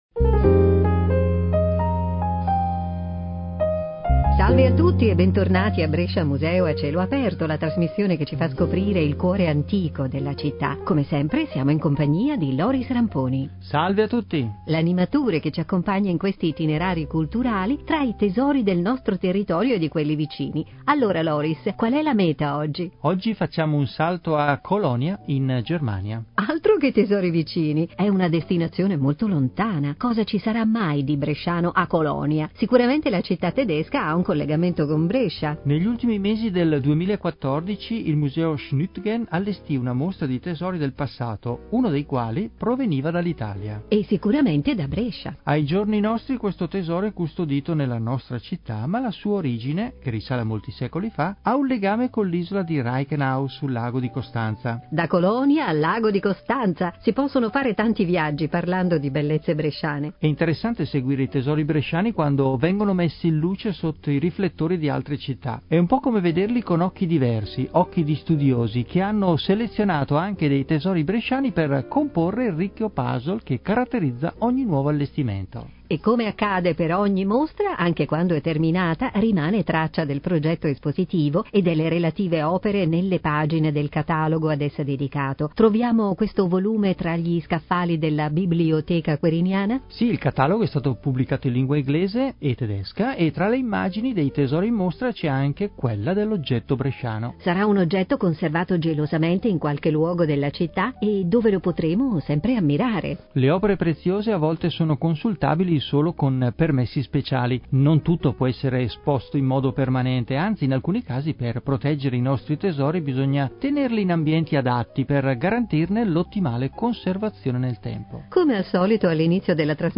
andata in onda il 27 novembre 2016 su Radio Brescia Sette